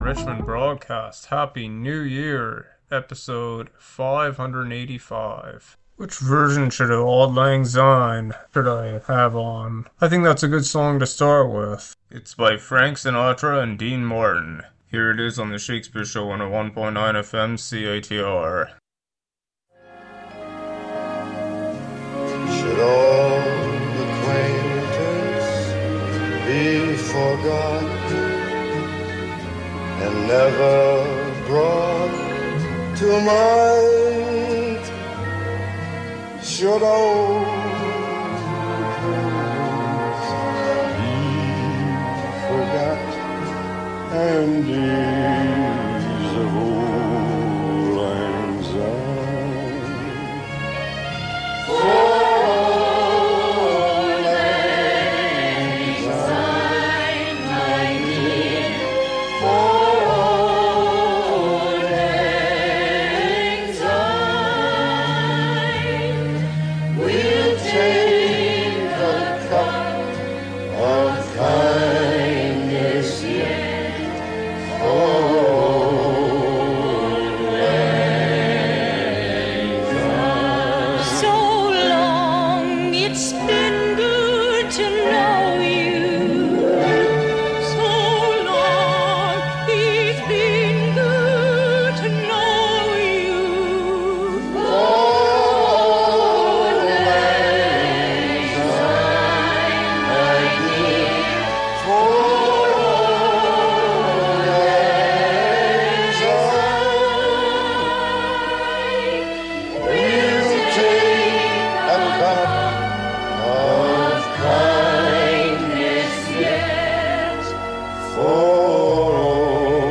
an eclectic mix of music, Happy new year!